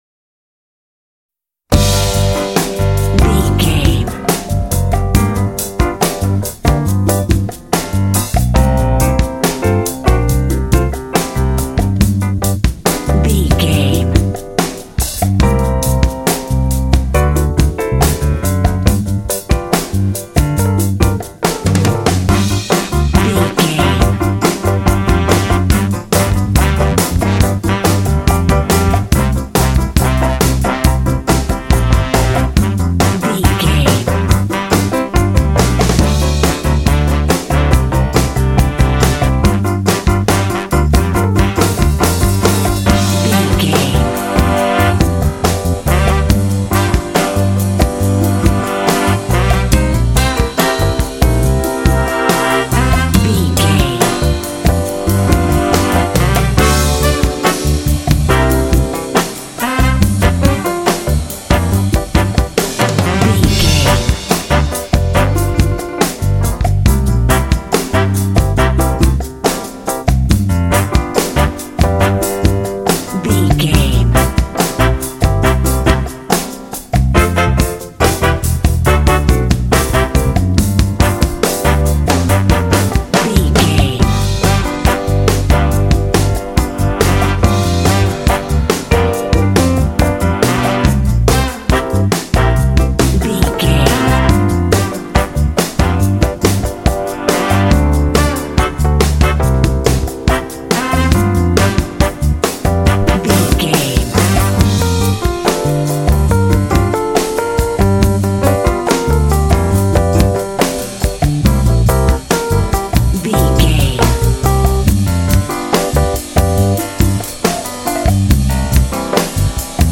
Aeolian/Minor
funky
groovy
bright
drums
bass guitar
piano
electric piano
brass
jazz